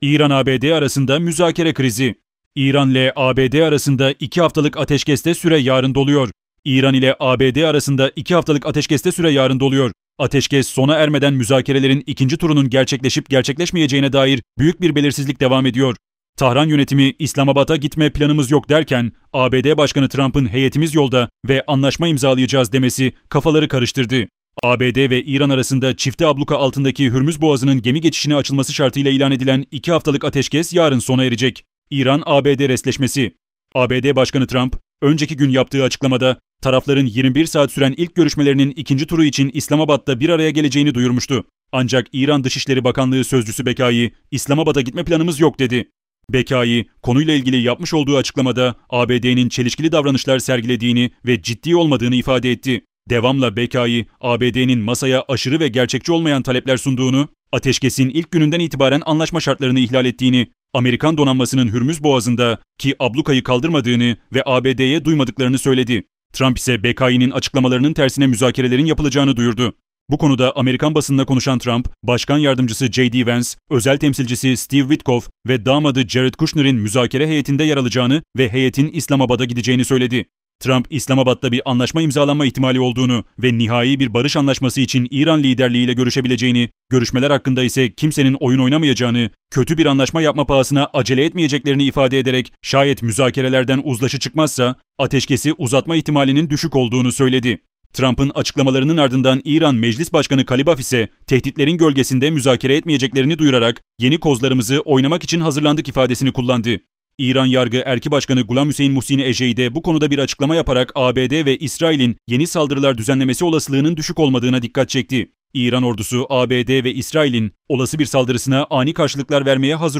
haberler_iran-abd-arasinda-muezakere-krizi.mp3